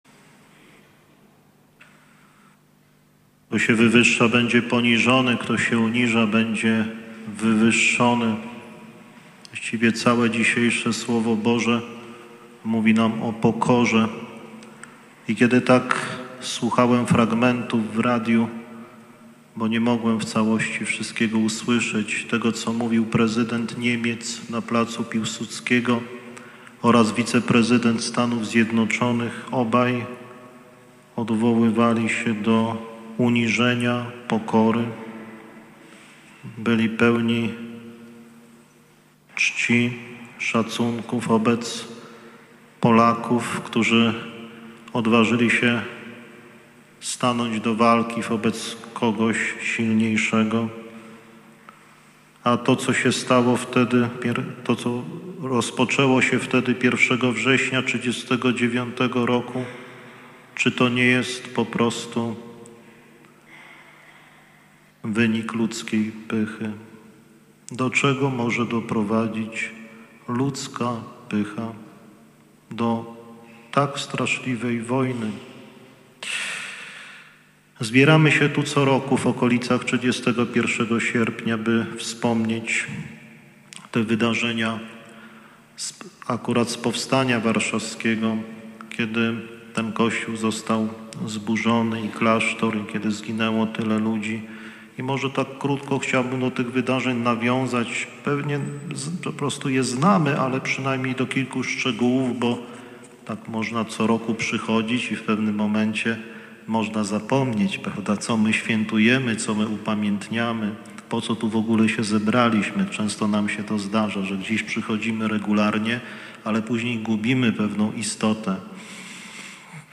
Msza św. za poległych w Powstaniu Warszawskim
W 75. rocznicę śmierci pod gruzami kościoła św. Kazimierza 4 kapłanów, 37 sióstr, 12 sierot i ok. 1000 mieszkańców Warszawy została odprawiona Msza. św. za poległych w Powstaniu Warszawskim.
homilia-1-IX-1.mp3